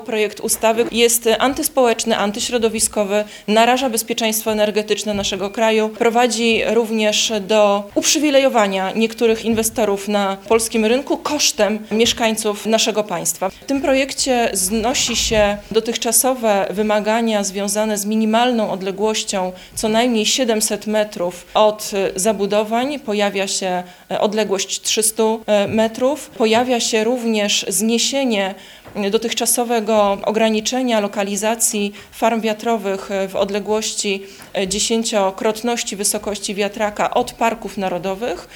O rzekomym udziale lobbystów w pracach nad tzw. ustawą wiatrakową podczas dzisiejszej konferencji prasowej mówili posłowie Prawa i Sprawiedliwości.